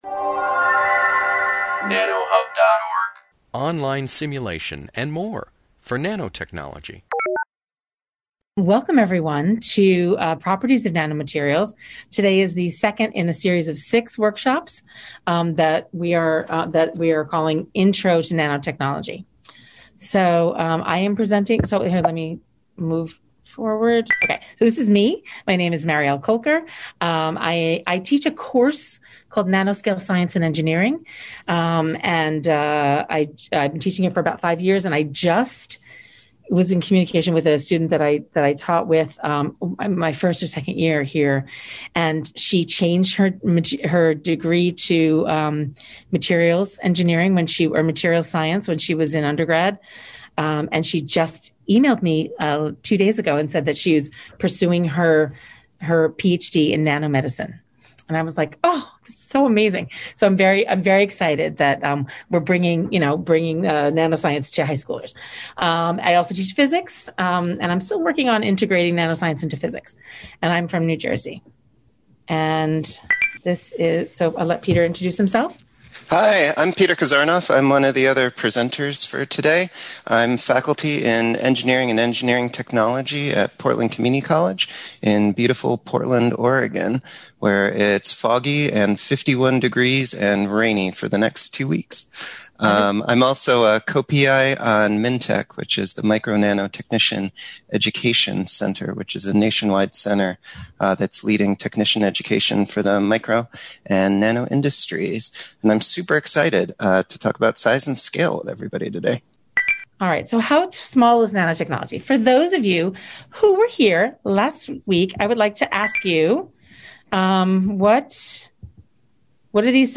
This webinar, published by the Nanotechnology Applications and Career Knowledge Support (NACK) Center at Pennsylvania State University, discusses properties of nanomaterials. During the webinar, presenters introduce nanotechnology, highlighting the effect of size and scale of on material properties. Covered are topics such as light-matter interactions at the nanoscale, force interactions at the nanoscale, quantum effects.